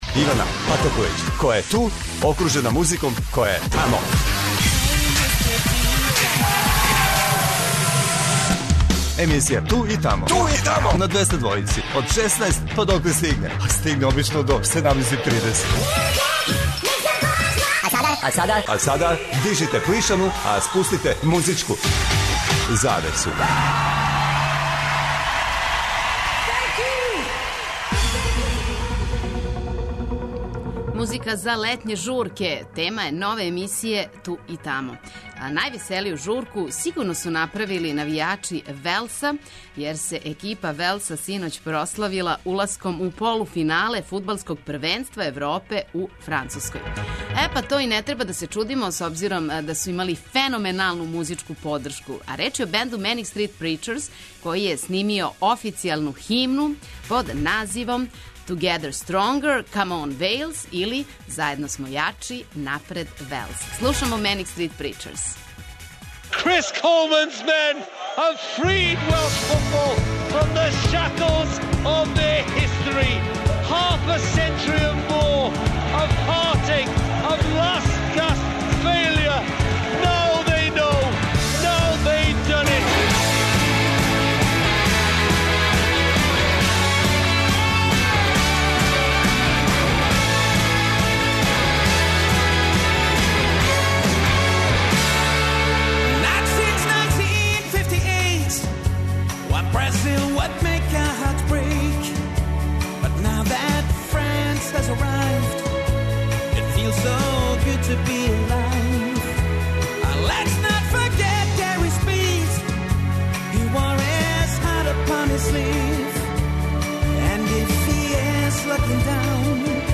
У емисији 'Ту и тамо' и даље летња атмосфера. Ове суботе слушаћемо избор песама идеалан за незаборавне летње журке.